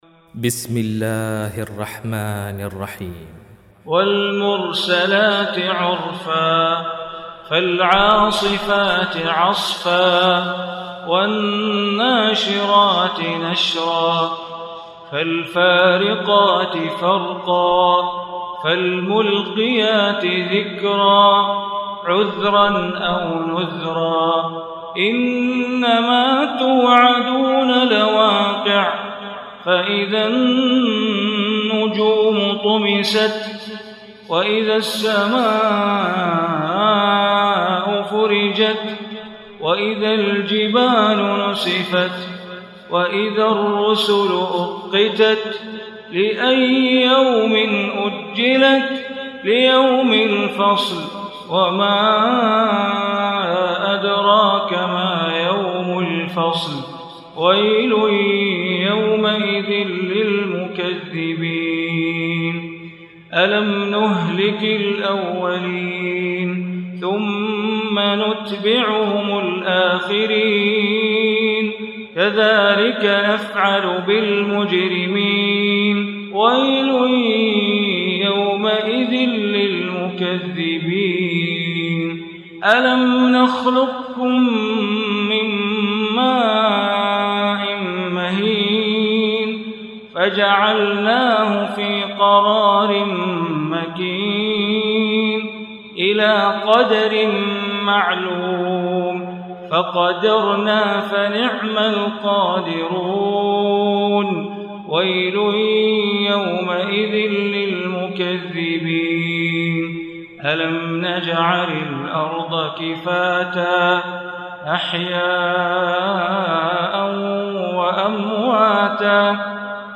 Surah Mursalat Recitation by Sheikh Bandar Baleela
Surah Mursalat, listen online mp3 tilawat / recitation in Arabic in the voice of Imam e Kaaba Sheikh Bandar Baleela.
77-surah-murasalat.mp3